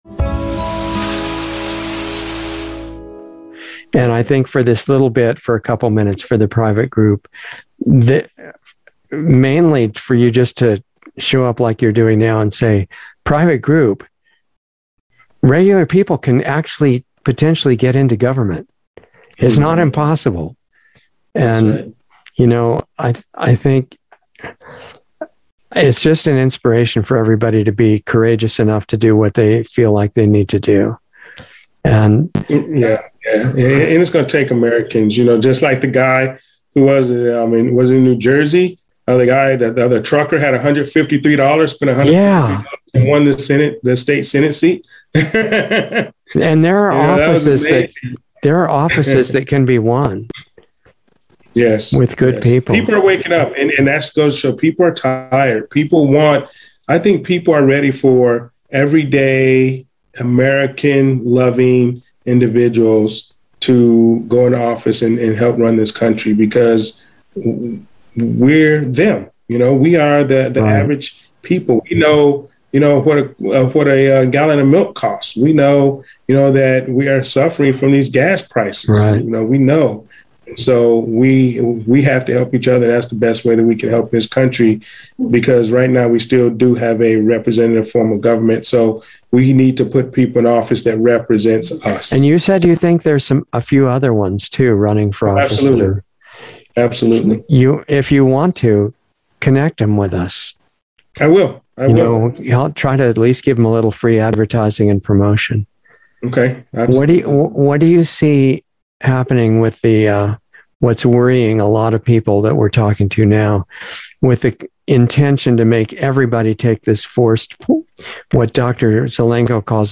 Insider Interview 11/8/21